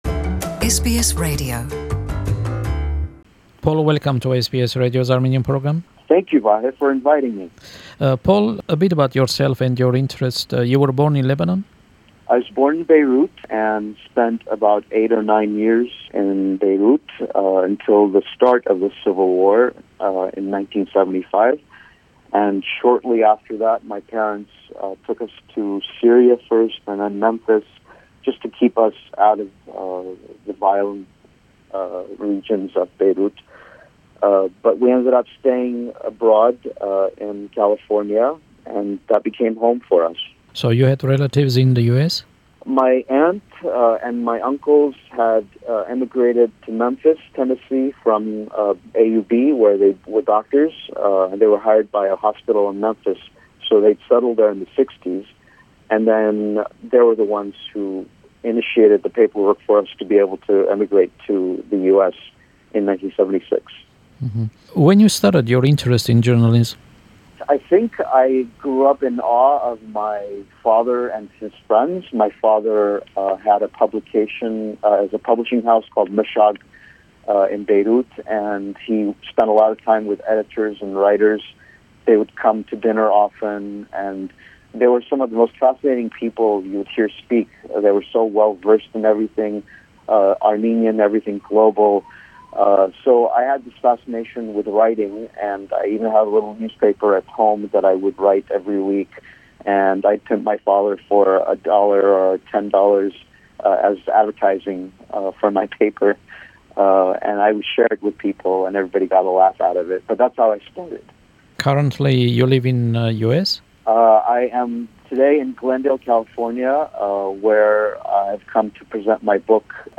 An interview with broadcast journalist